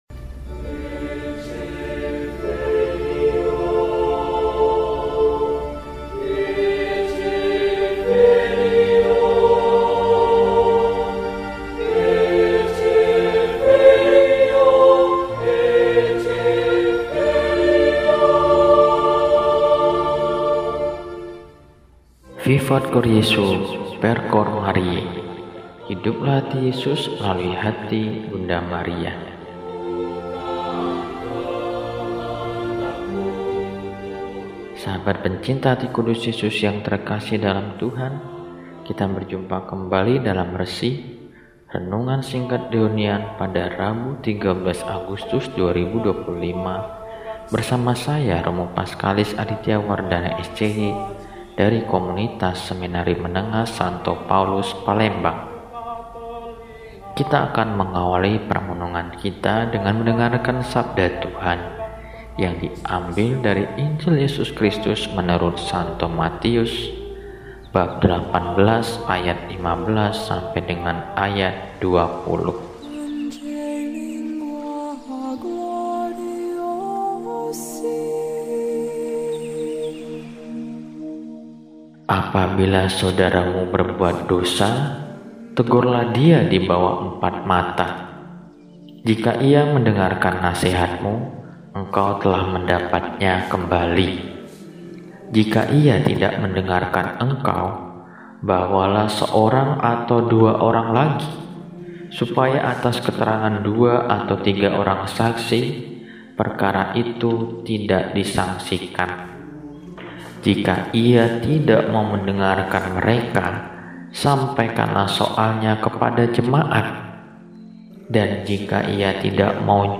Rabu, 13 Agustus 2025 – Hari Biasa Pekan XIX – RESI (Renungan Singkat) DEHONIAN